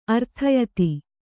♪ arthayati